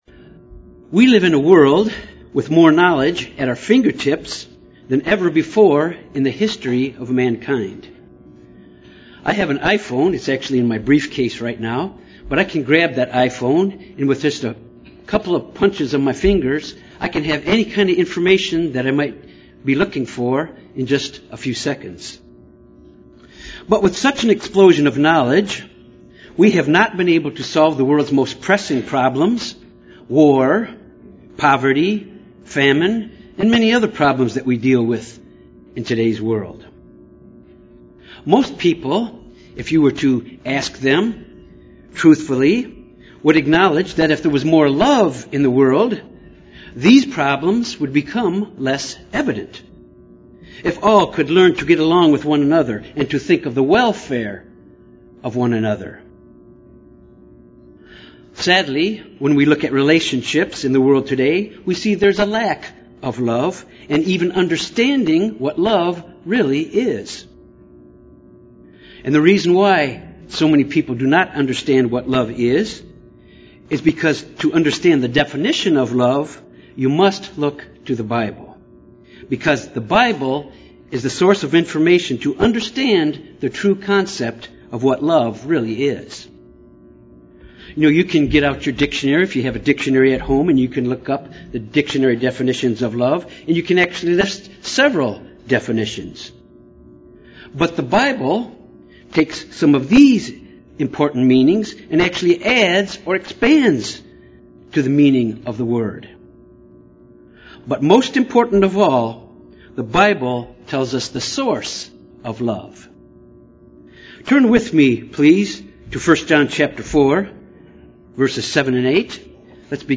This sermon deals with examining the first four commandments concerning love toward God in our lives, the measuring stick of our relationship with God.